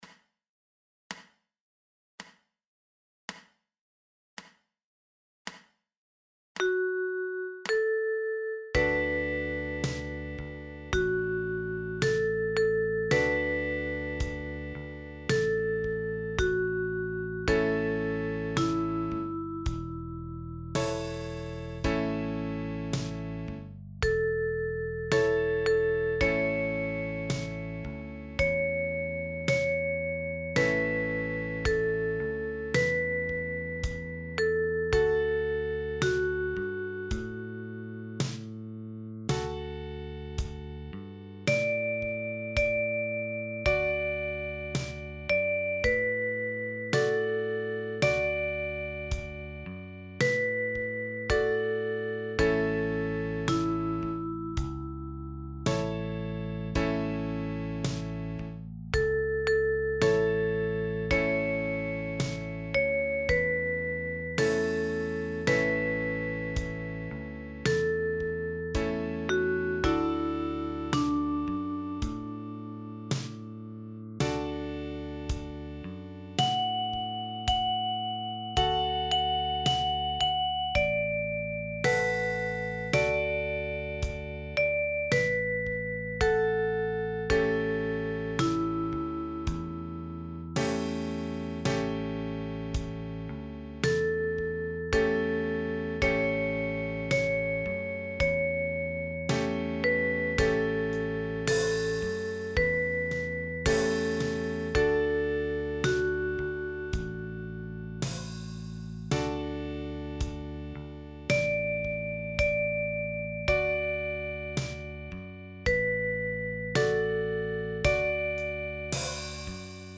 einfach notiert für die Tin Whistle